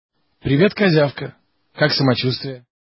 Список файлов рубрики Голоса